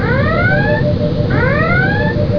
klaxon.wav